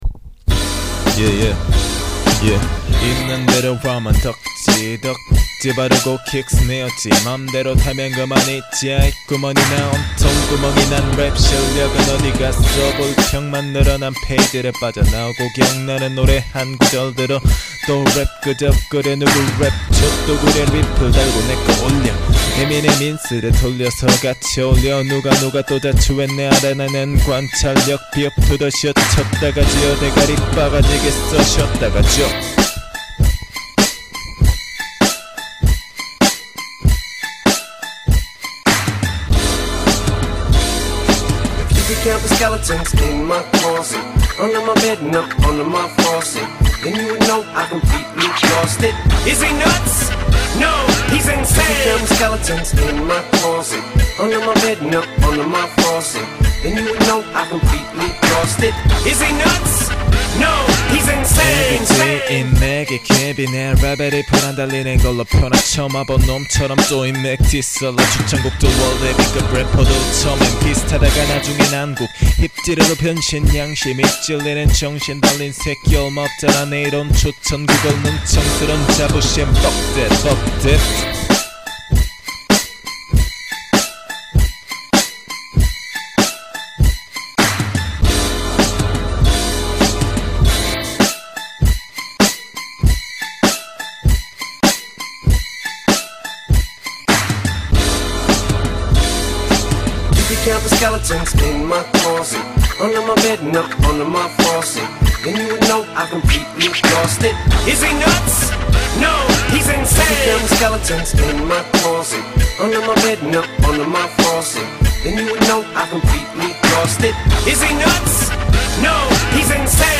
플로우 타지는대로 타고 소음제거조차안하고 그냥올린 싱싱한 막장벙개입니다 ㅋㅋ;;;